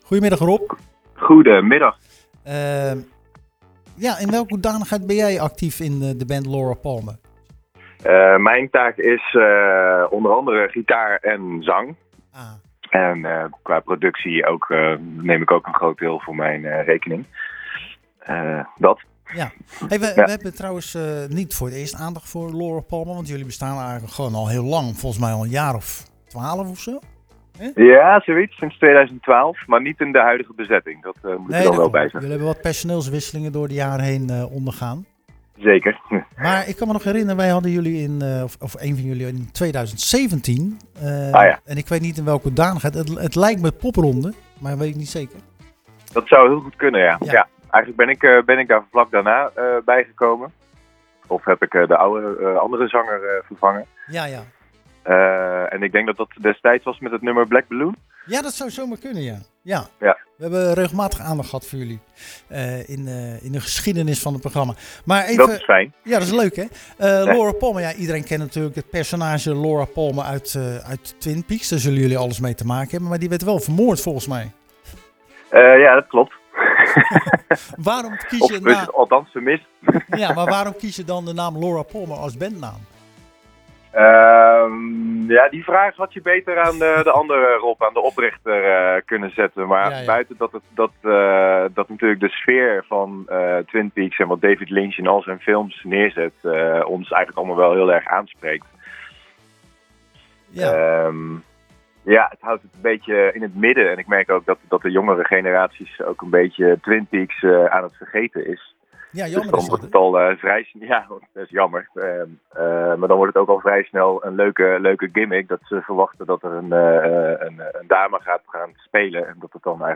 We belde tijdens het programma Zwaardvis met zanger/gitarist